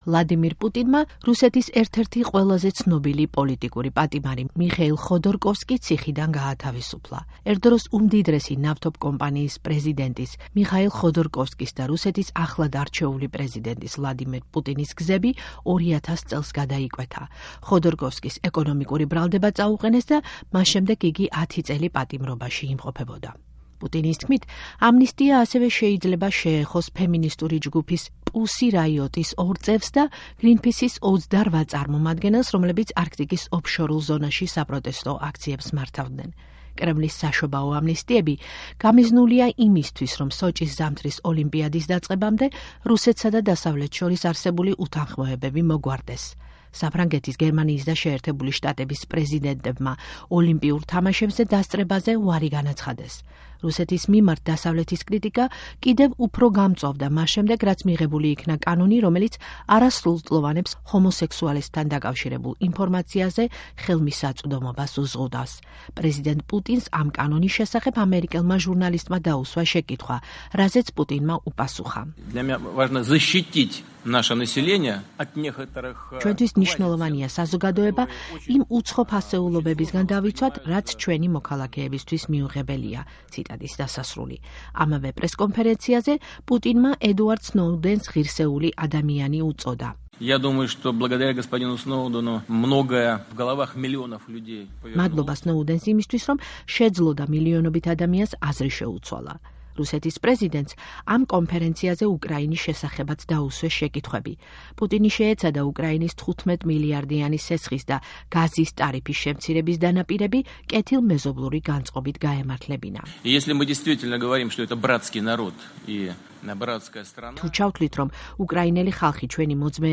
პუტინის პრესკონფერენცია